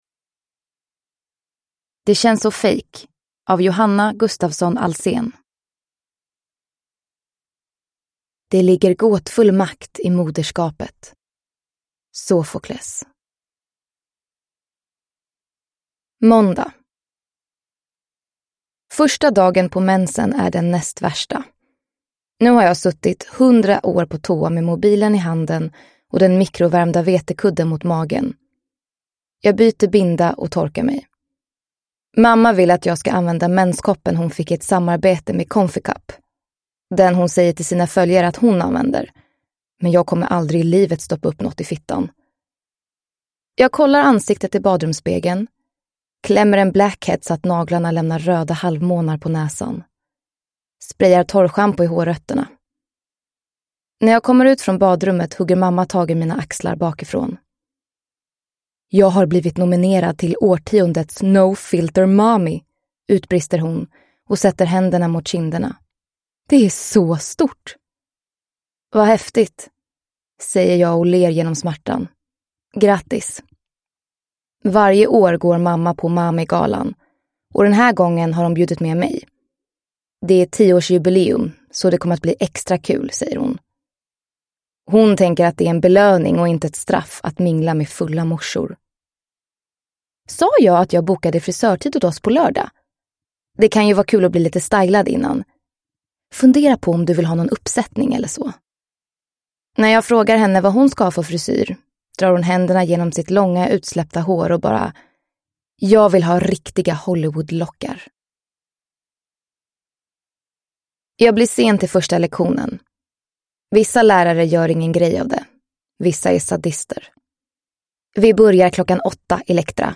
Det känns så fake – Ljudbok